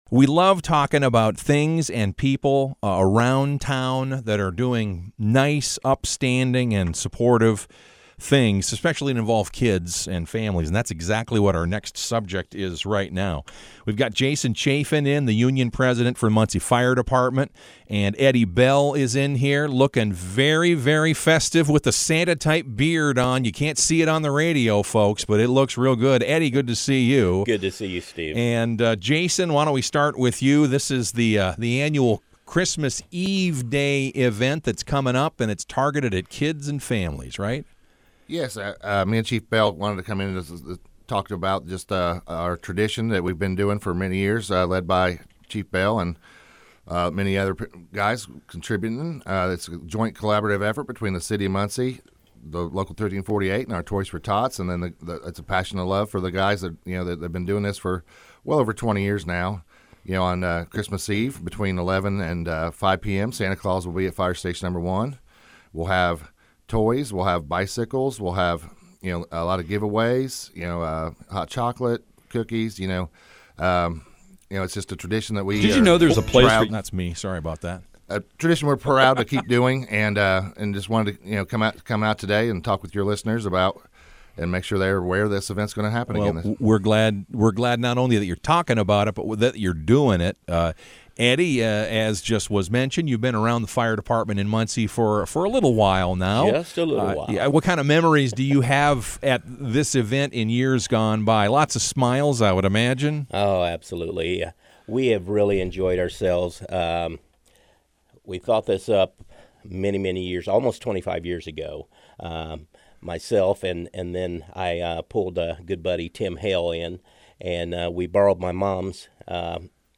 About the event as broadcast by WMUN.